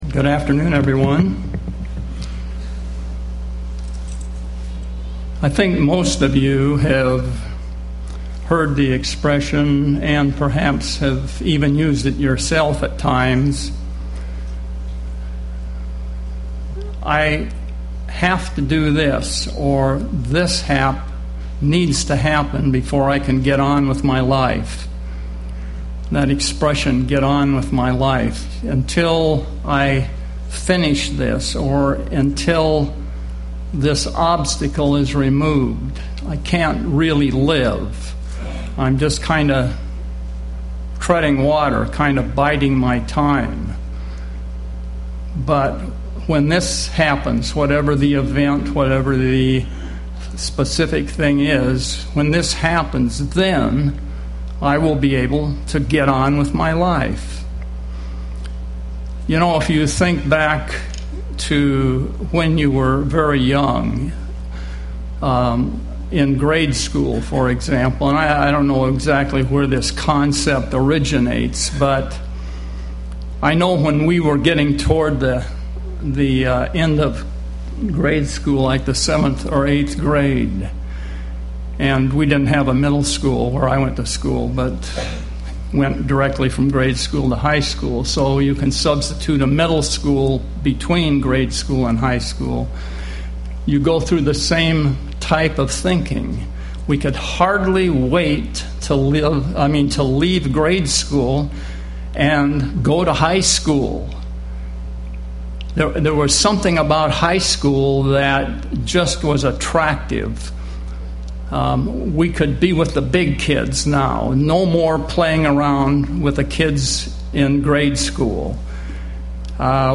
Given in Seattle, WA
UCG Sermon Studying the bible?